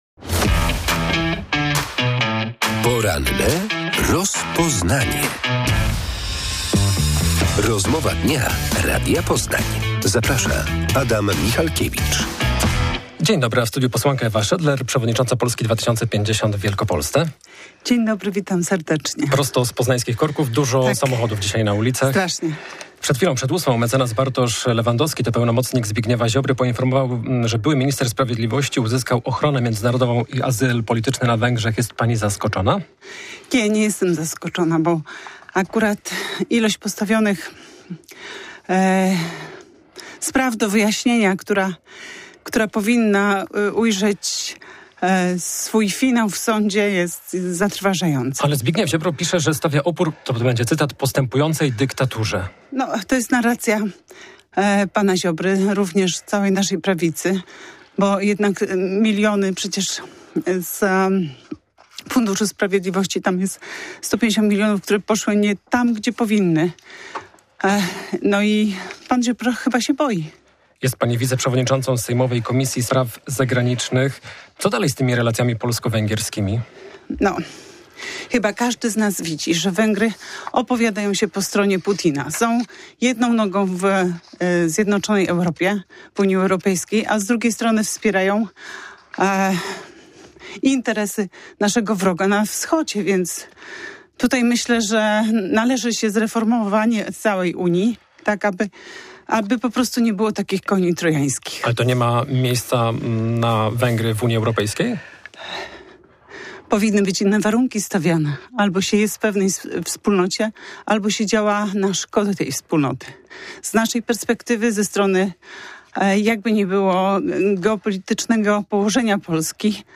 Były minister sprawiedliwości poinformował dziś w mediach społecznościowych, że otrzymał azyl polityczny na Węgrzech. "Pan Ziobro chyba się boi" - stwierdziła dziś w porannej rozmowie Radia Poznań wiceprzewodnicząca sejmowej komisji spraw zagranicznych Ewa Schädler z Polski 2050.